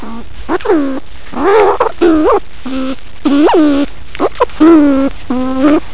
Scoiattolo
• (Sciurus vulgaris)
scoiattolo.wav